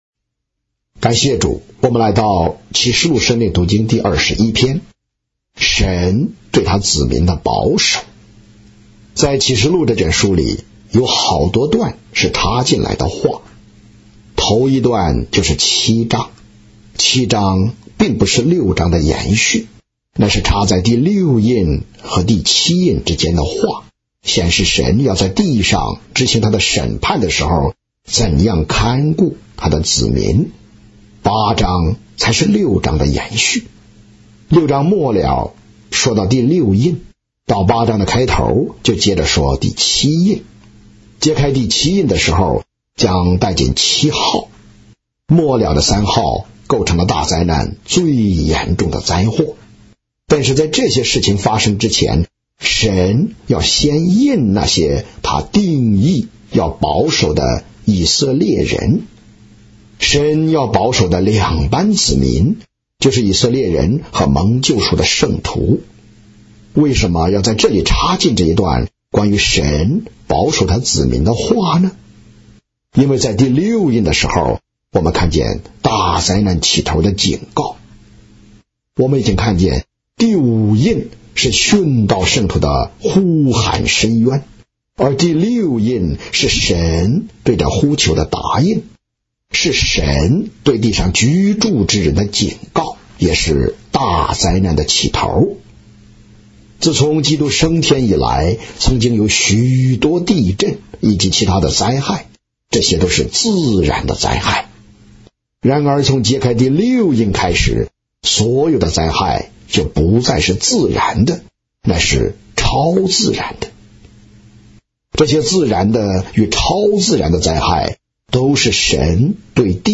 👆延伸讀經段落及註解：啟七1至17及註🔉語音播放生命讀經📃新約聖經恢復本(紙本)：P1260~P1263📃啟示錄生命讀經(紙本)：P300~P315